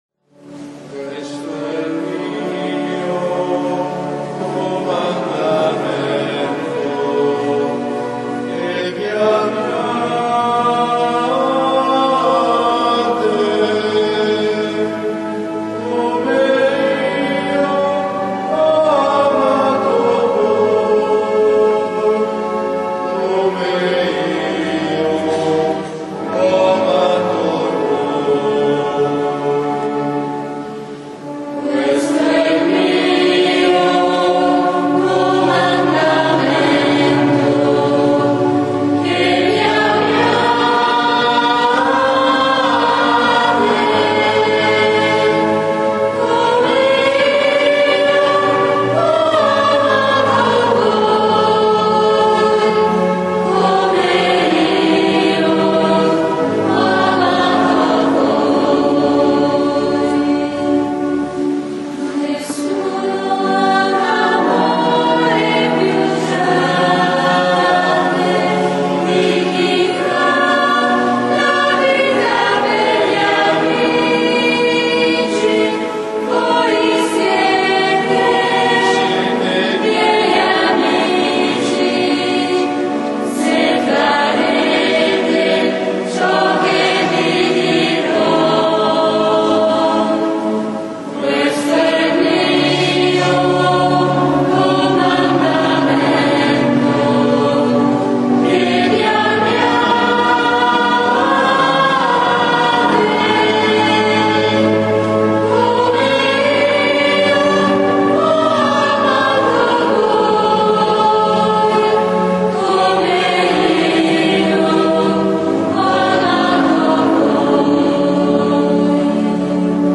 Celebrazione della Cena del Signore